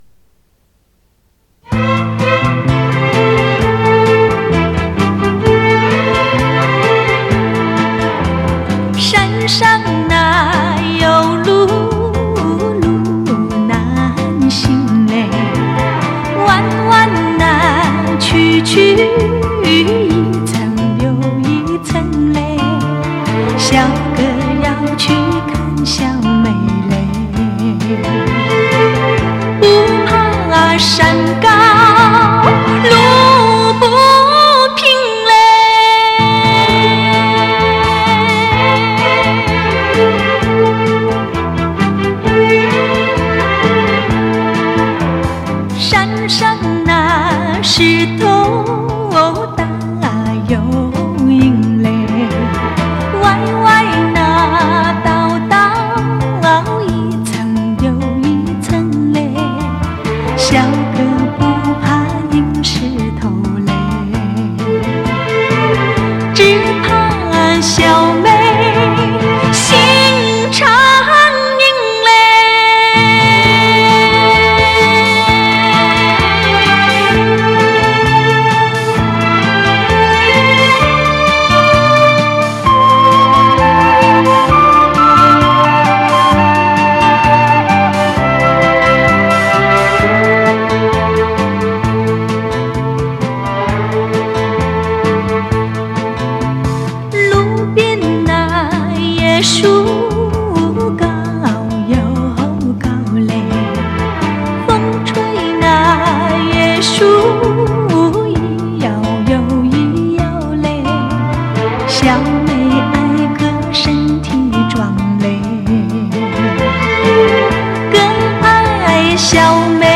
磁带数字化：2022-09-17